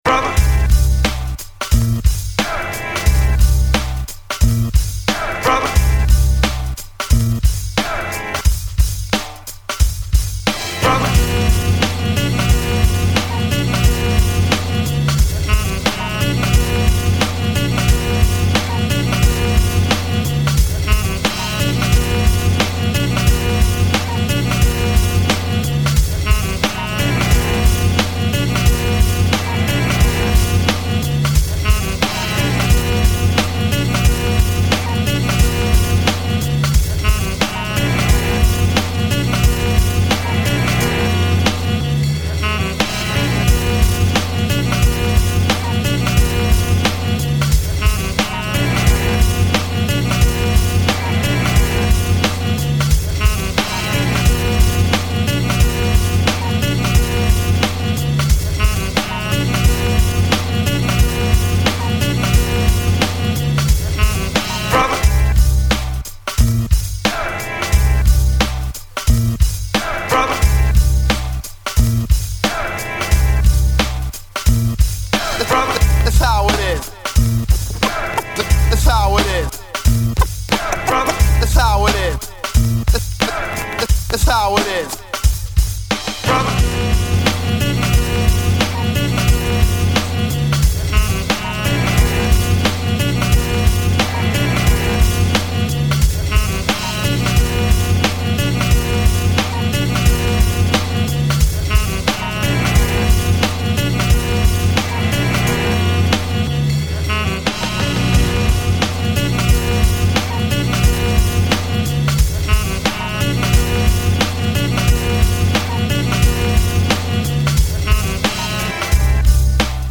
＊試聴はダイジェストです。
Mix CD , Sampling Beats
Underground Hip Hop その他のおすすめレコード